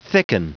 Prononciation du mot thicken en anglais (fichier audio)
Prononciation du mot : thicken